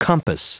Prononciation du mot compass en anglais (fichier audio)
Prononciation du mot : compass